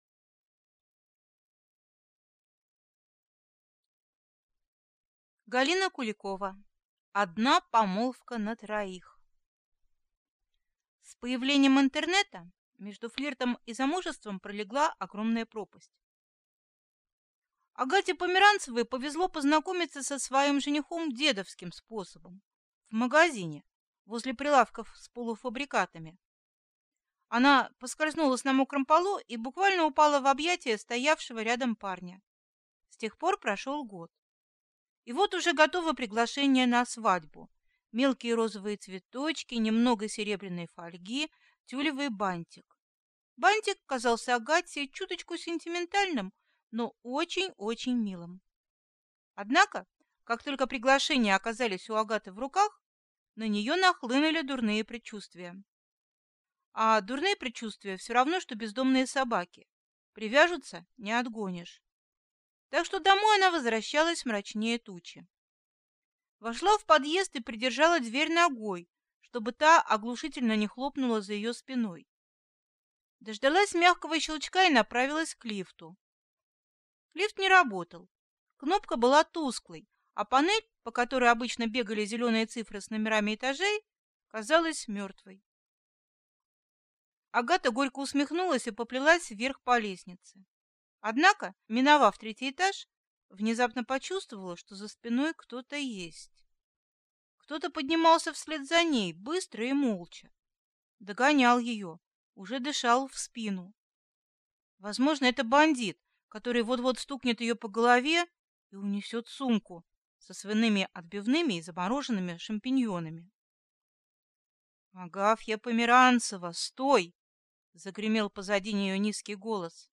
Аудиокнига Одна помолвка на троих | Библиотека аудиокниг